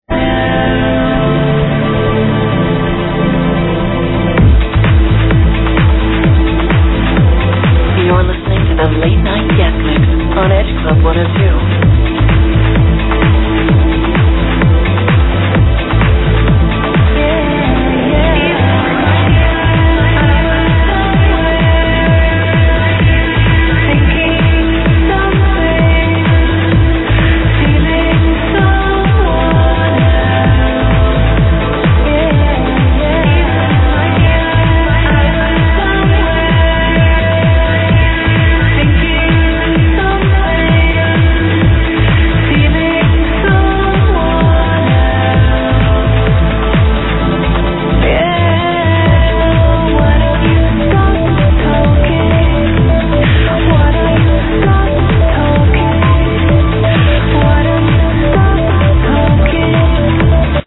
Female Vocals over Mellow Trance Track
It was ripped from a local radio station weekend dance mix.